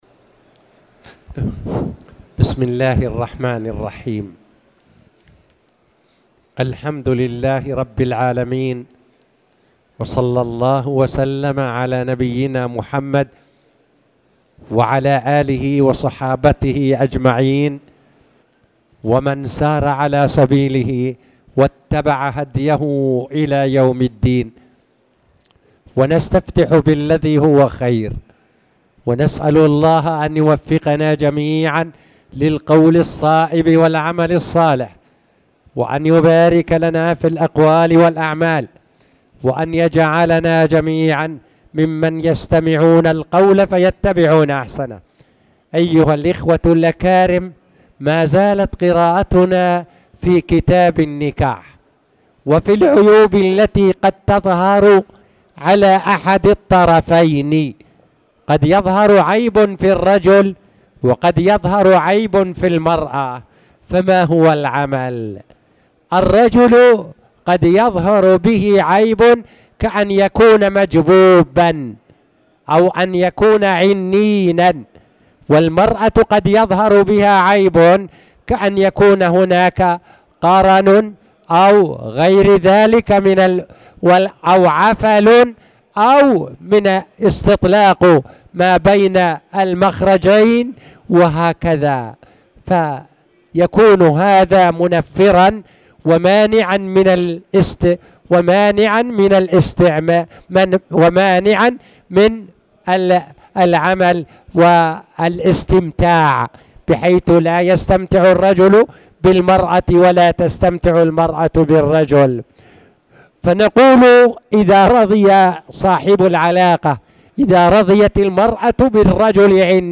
تاريخ النشر ٢٩ ربيع الأول ١٤٣٨ هـ المكان: المسجد الحرام الشيخ